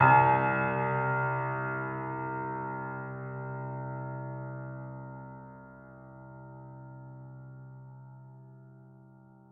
Steinway_Grand
c1.mp3